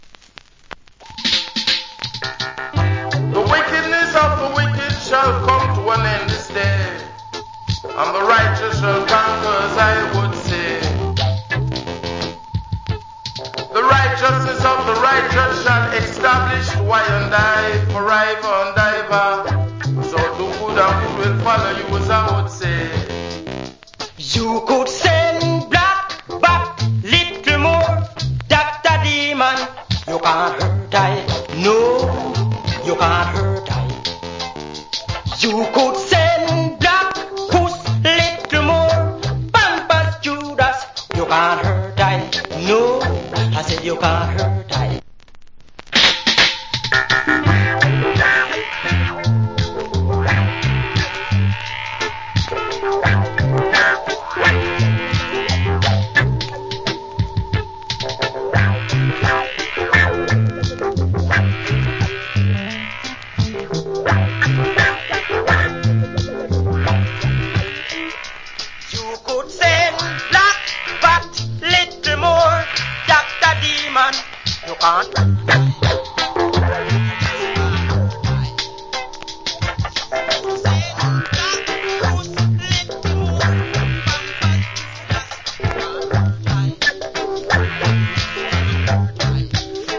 Wicked Reggae Vocal.